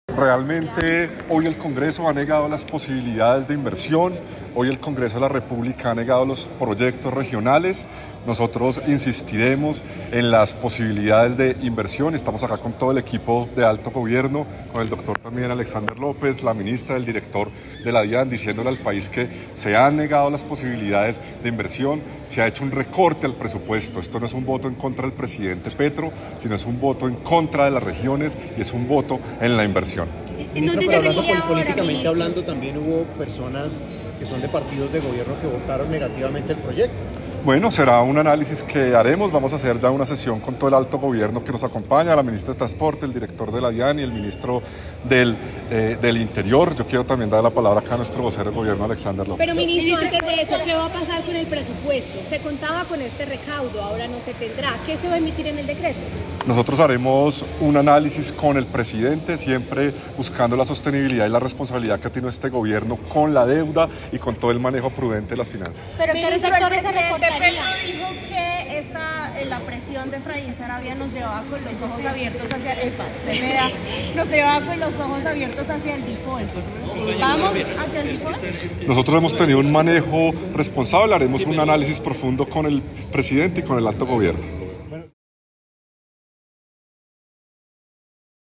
12. Declaración a medios 11 de diciembre de 2024
Stereo